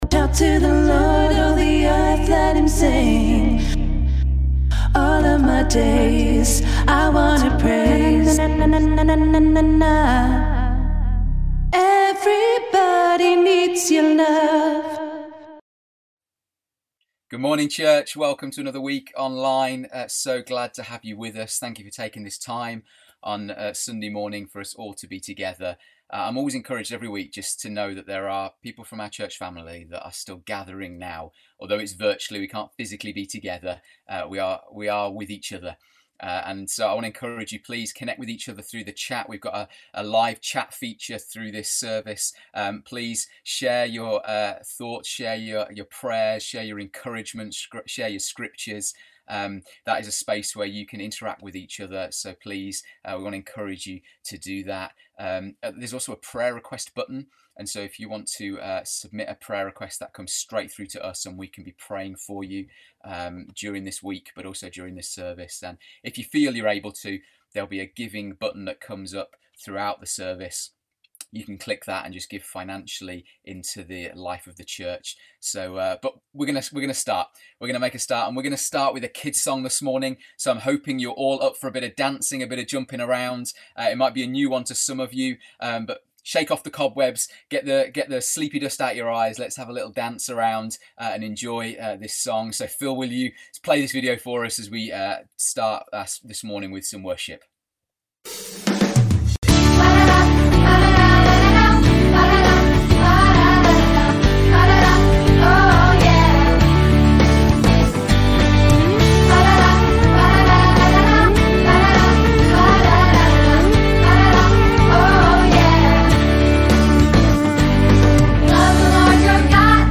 Listen again to our church online gathering.